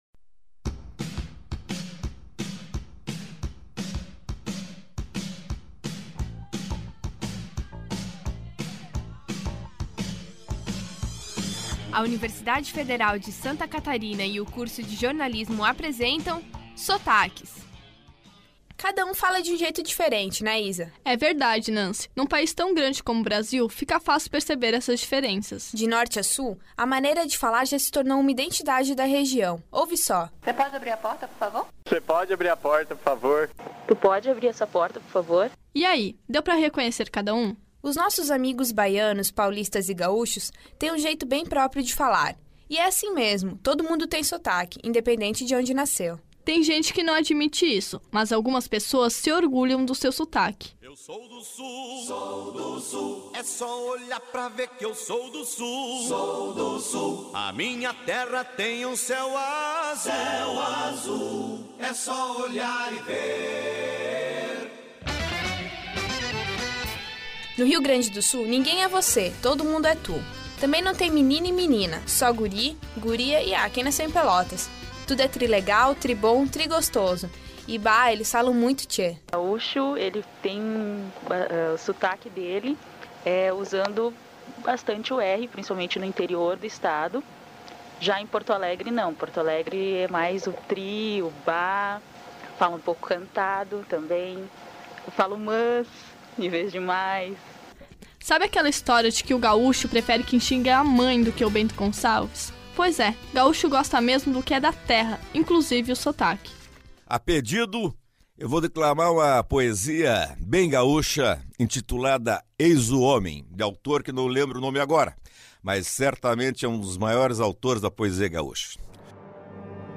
Sotaques
Em um país tão grande, é compreensível ter tantas variáveis de sotaques. De sul a norte, escute-os aqui.
Sotaques.mp3